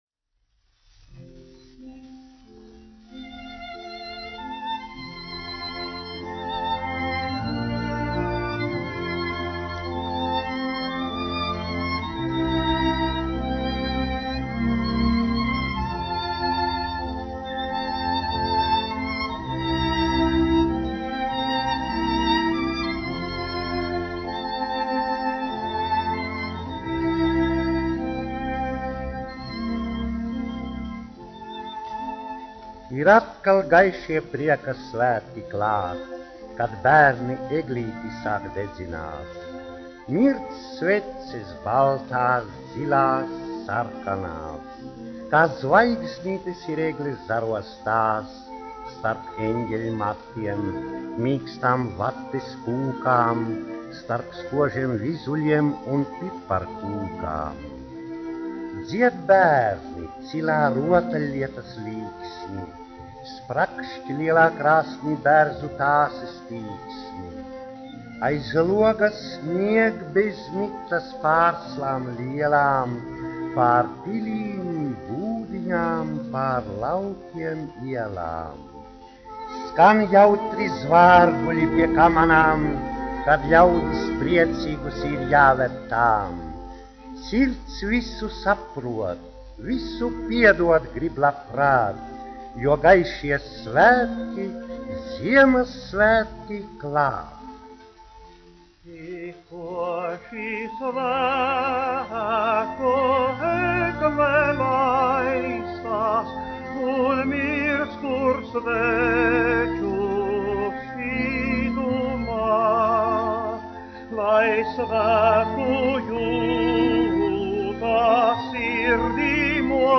1 skpl. : analogs, 78 apgr/min, mono ; 25 cm
Ziemassvētku mūzika
Monologi ar mūziku
Skaņuplate